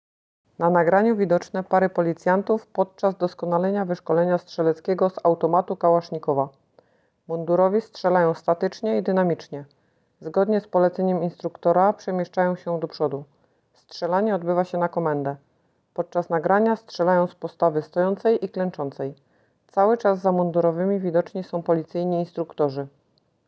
W czasie treningu stróże prawa strzelali w parach, statycznie i dynamicznie.